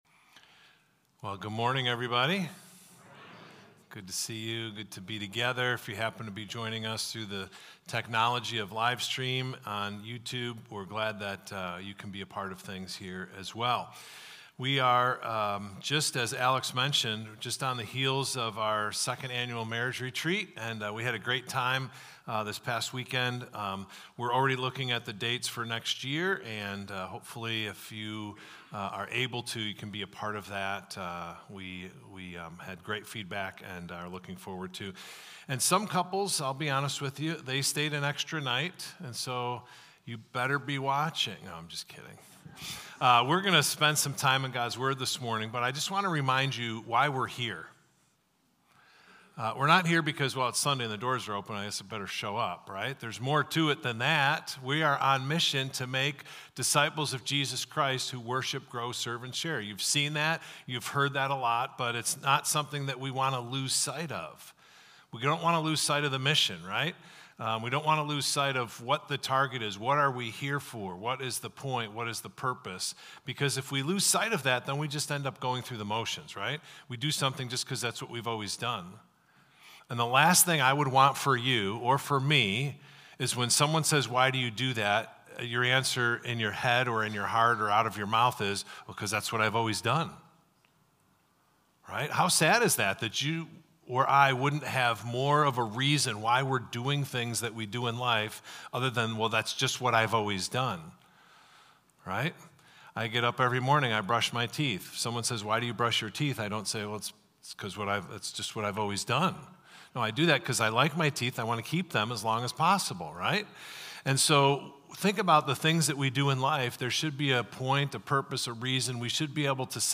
Victor Community Church Sunday Messages / 24/7 Series: Fully Committed to Praise Him